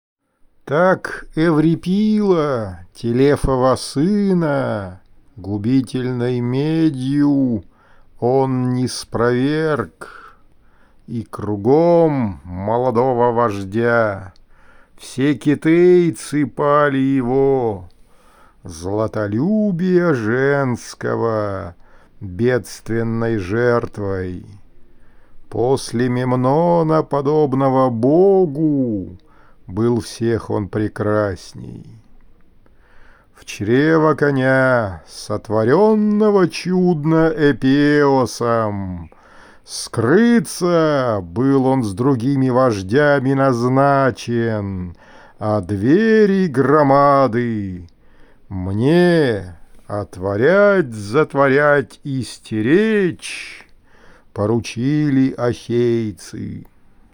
Одиссея. Песня одиннадцатая: Декламация